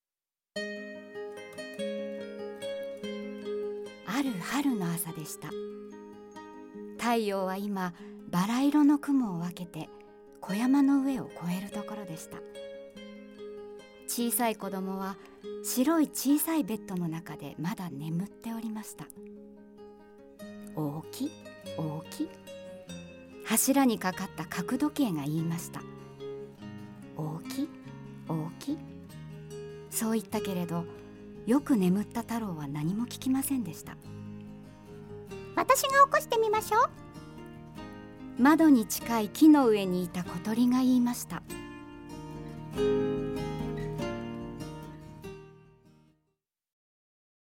朗読
ボイスサンプル